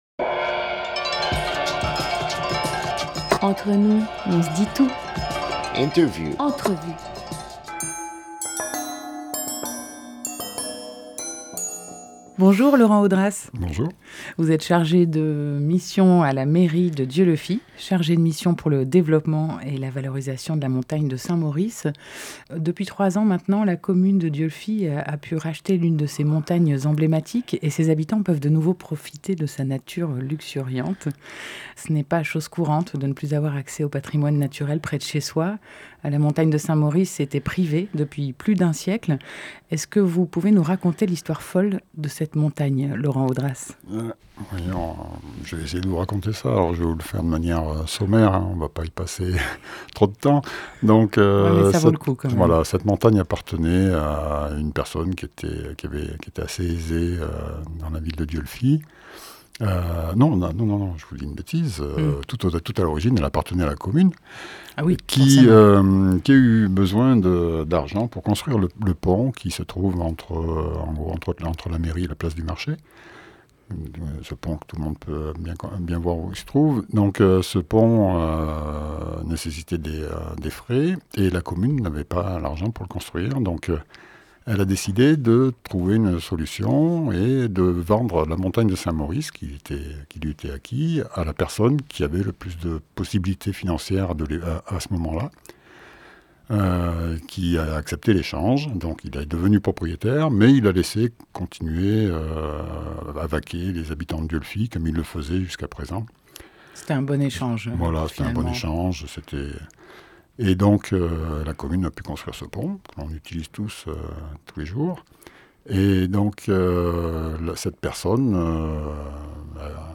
25 décembre 2017 16:53 | Interview